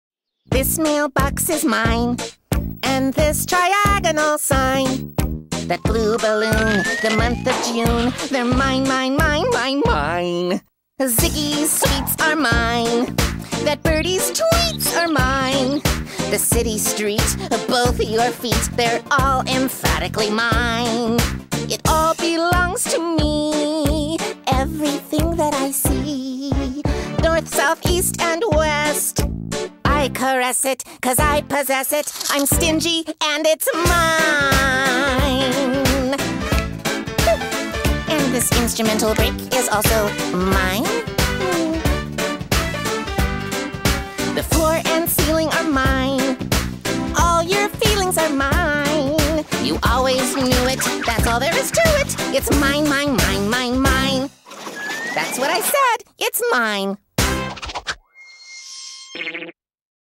BPM90
Audio QualityPerfect (High Quality)